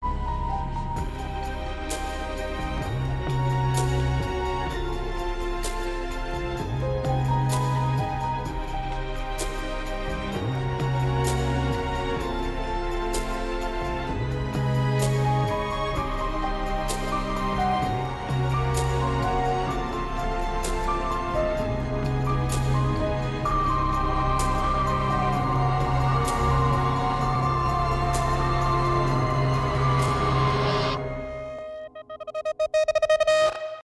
без слов
ost
инструментальные , интригующие